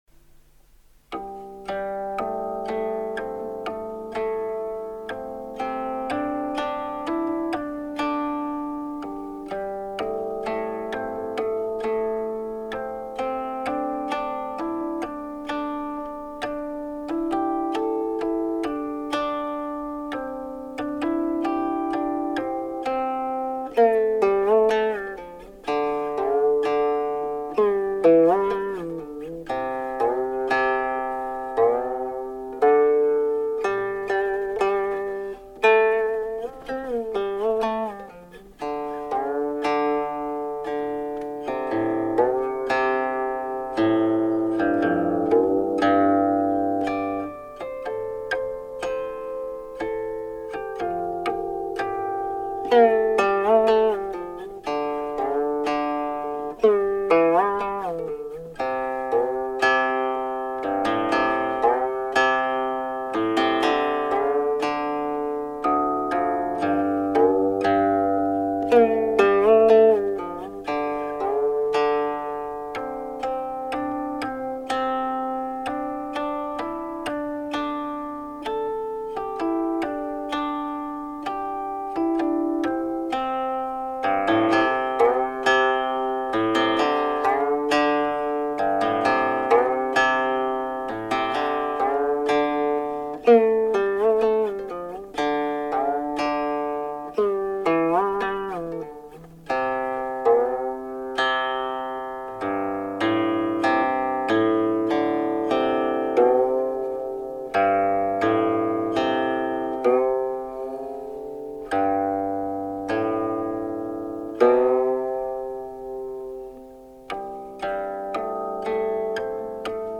湘妃怨：分明曲里愁云雨 似道萧萧郎不归。。。（古琴） 激动社区，陪你一起慢慢变老！